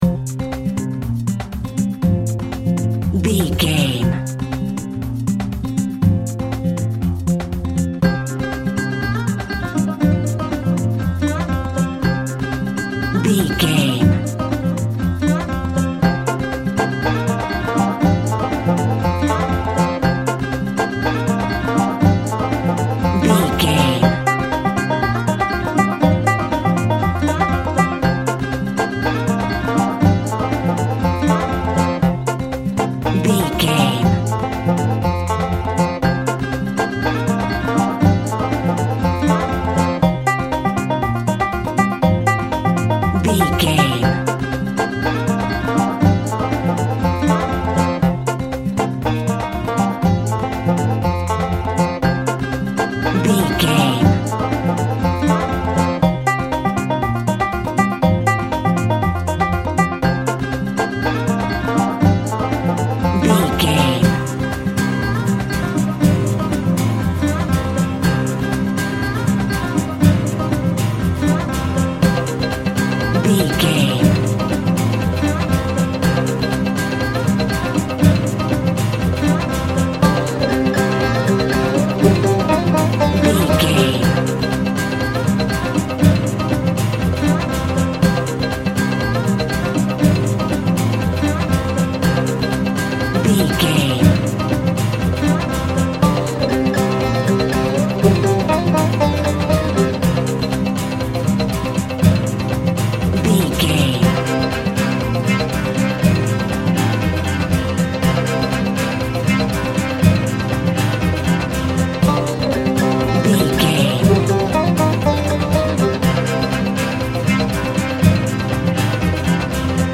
Uplifting
Aeolian/Minor
celtic
Synth Pads
ambient synths
strings
new age synth